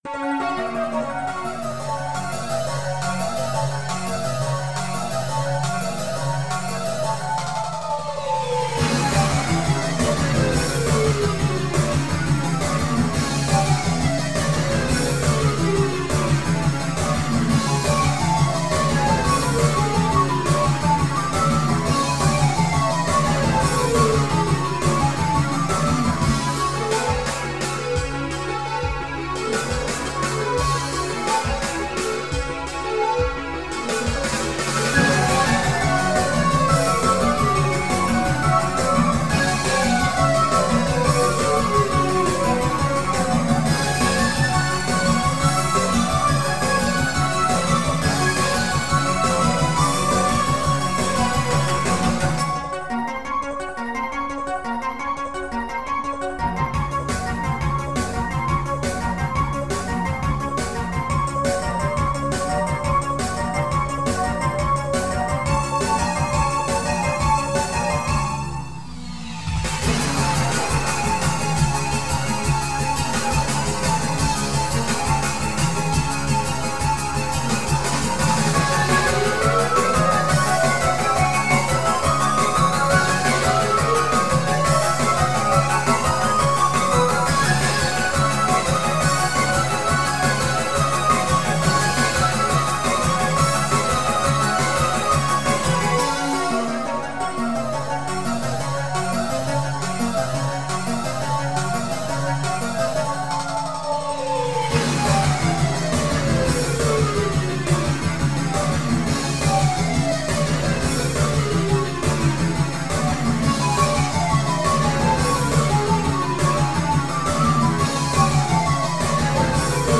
game BGM-like classical/folk and original music in mp3